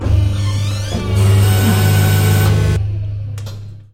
Звуки механизма робота
Все аудиофайлы записаны с реальных устройств, что придает им естественность.